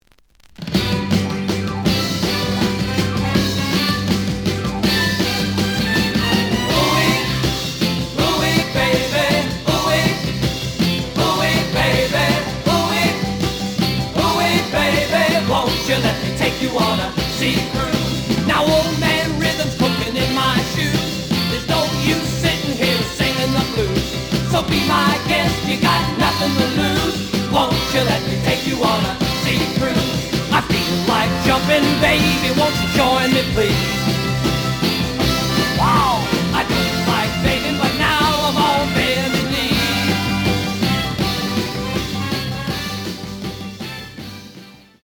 The audio sample is recorded from the actual item.
●Genre: Rhythm And Blues / Rock 'n' Roll
●Record Grading: VG~VG+ (傷はあるが、プレイはおおむね良好。Plays good.)